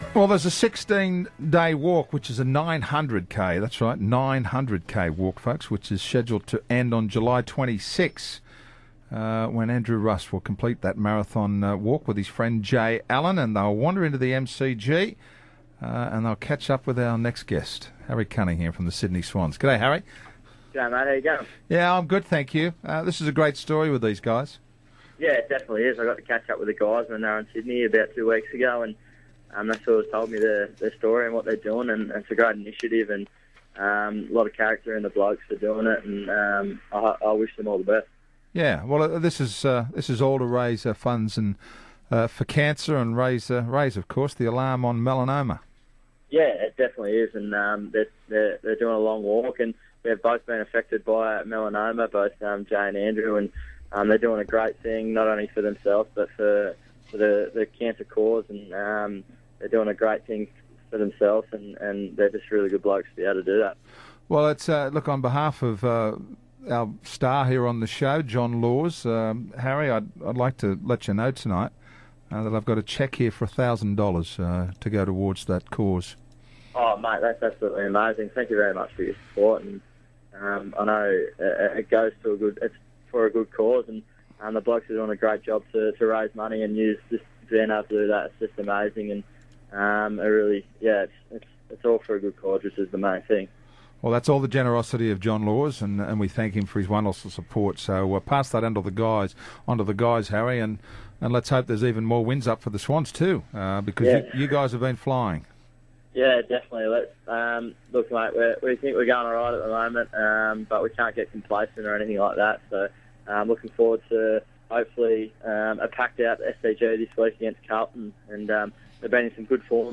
Harry Cunningham appeared on 2SM's Talkin' Sport program on Wednesday July 9, 2014